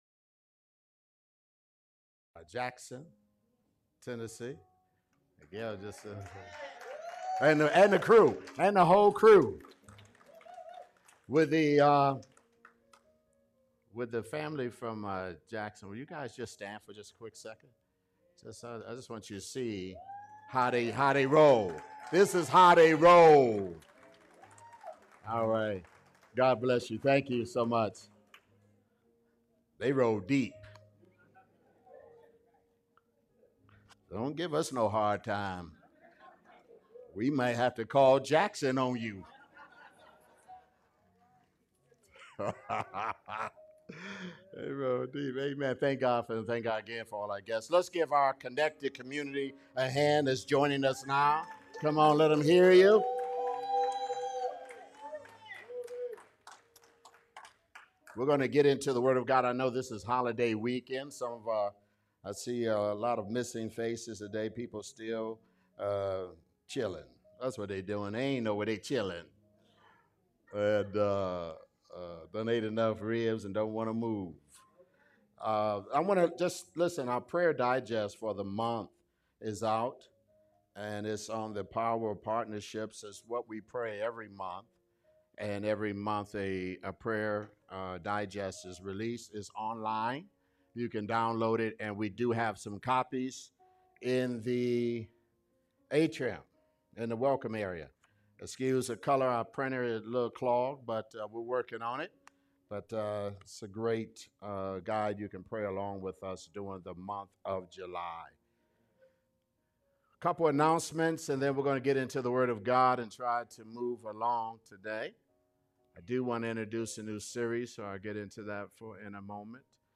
Sermons | Gen2Gen Ministries